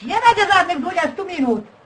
Worms speechbanks
hello.wav